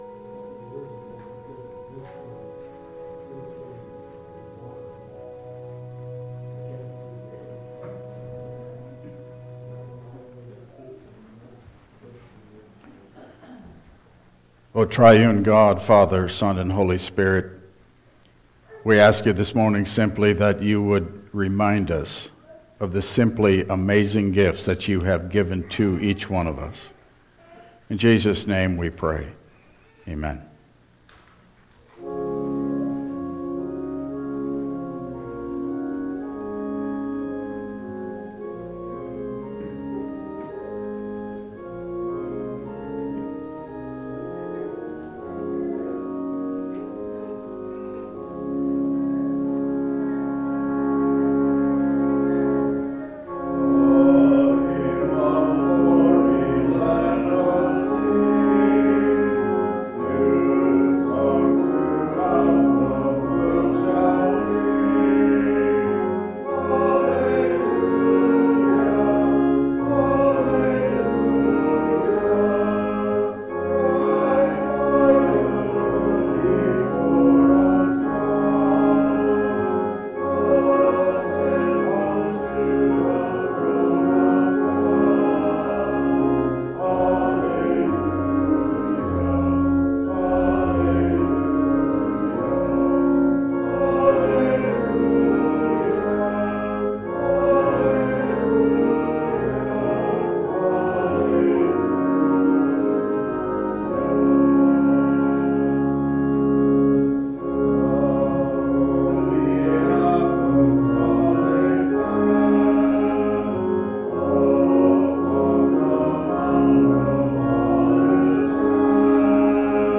Service Type: Regular Service